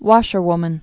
(wŏshər-wmən, wôshər-) also wash·wom·an (wŏshwmən, wôsh-)